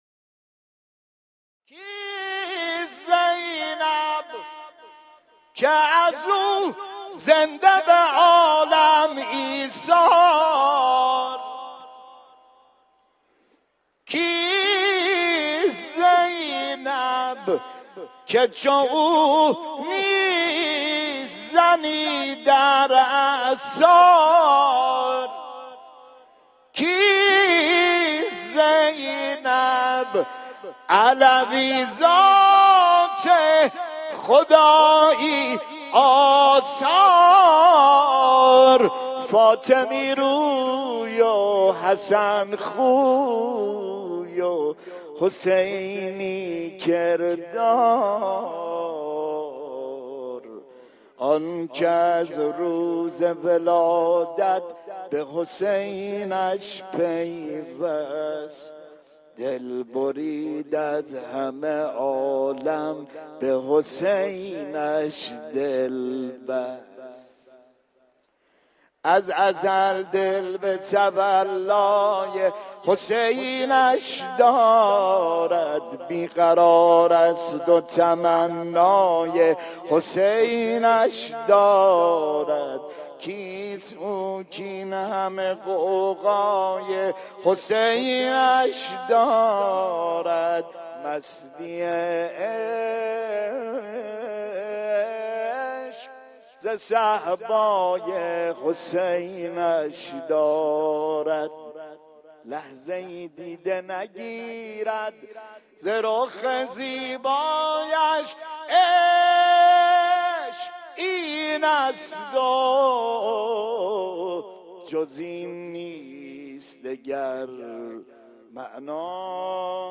مدح حضرت زینب